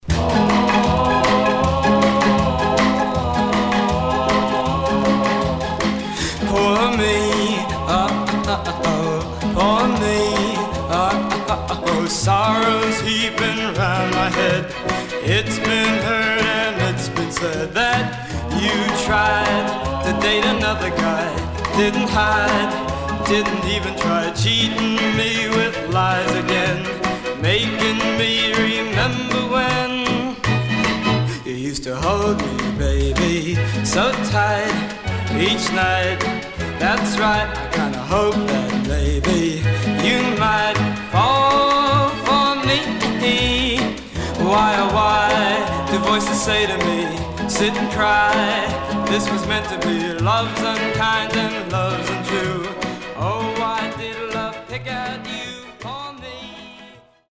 Stereo/mono Mono
Teen